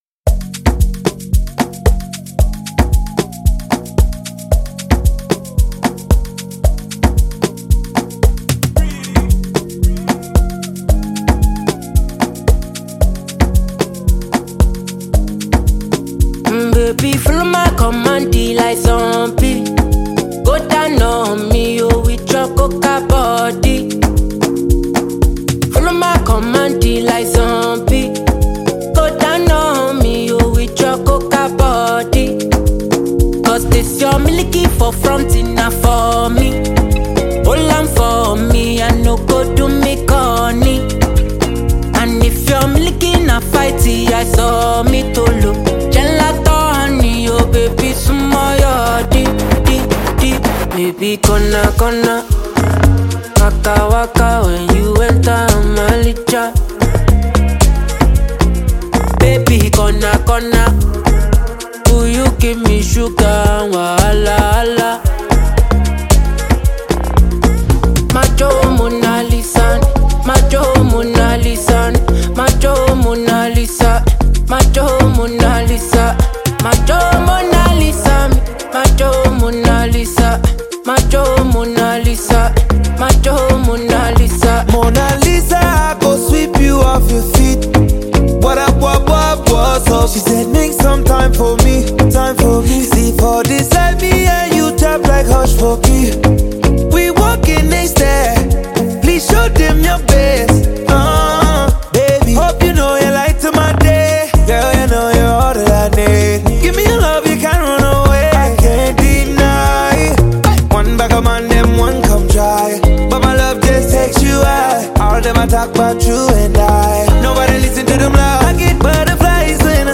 the singer features Ameircna veteran R&B singer